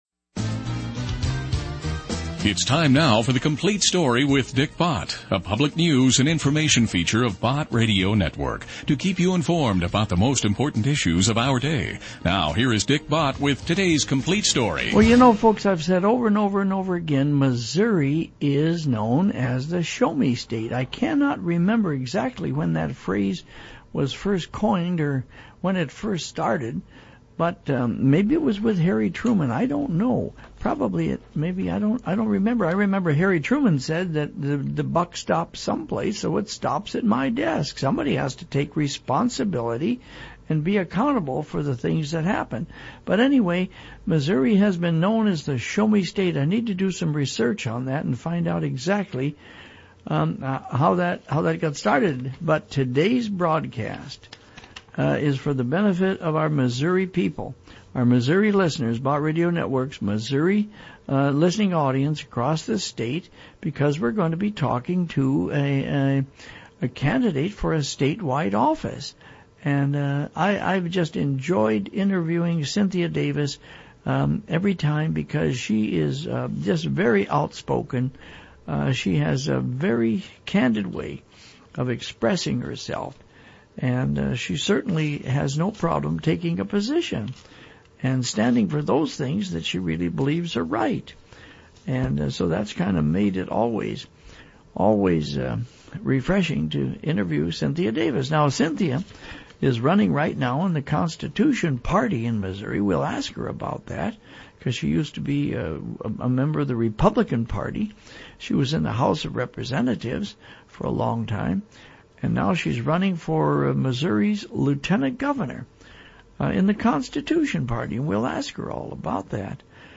Christian radio host